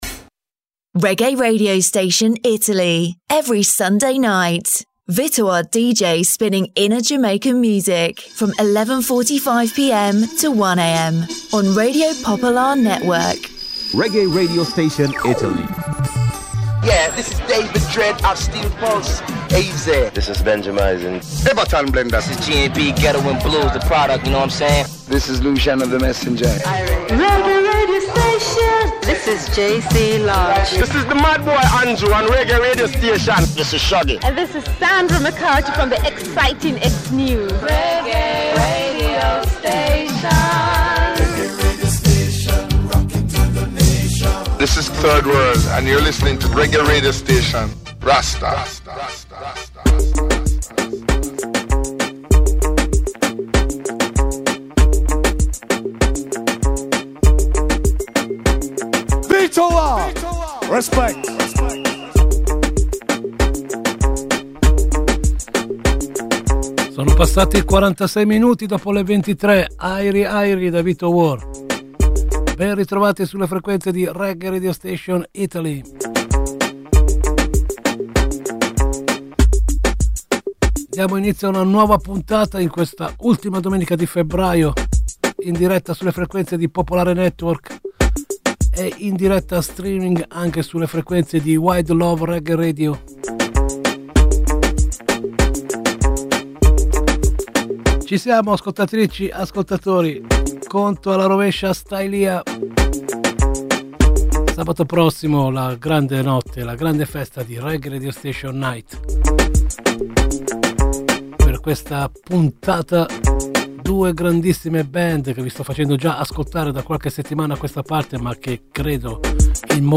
A ritmo di Reggae Reggae Radio Station accompagna discretamente l’ascoltatore in un viaggio attraverso le svariate sonorità della Reggae Music e sicuramente contribuisce non poco alla diffusione della musica e della cultura reggae nel nostro paese.